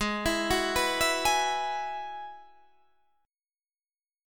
Abm7#5 Chord